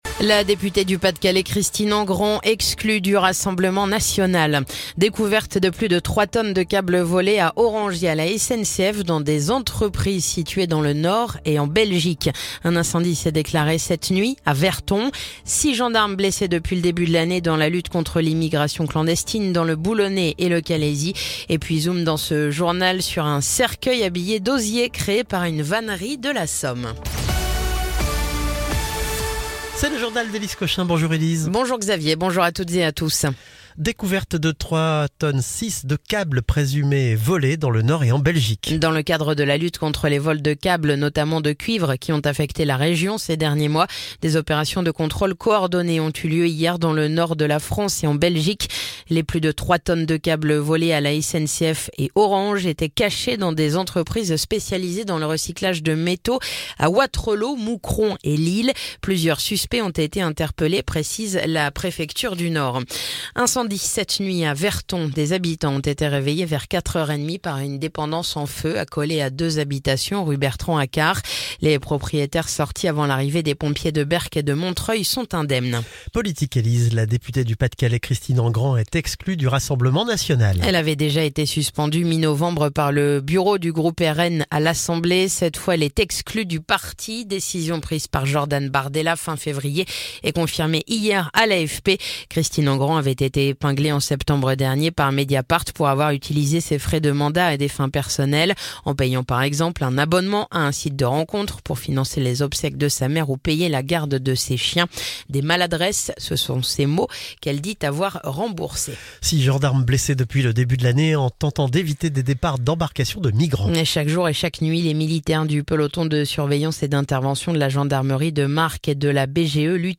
Le journal du mercredi 19 mars